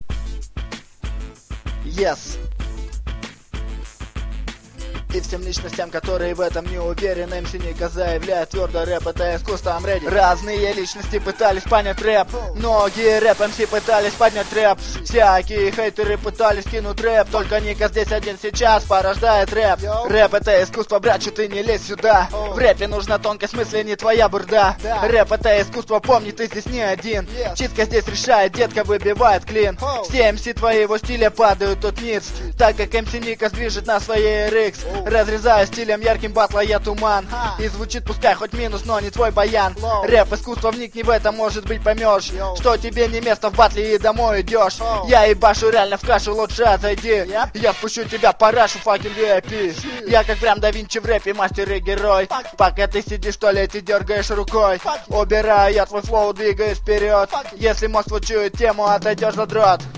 Хип хоп !!
Такой трек с более менее путевой читкой..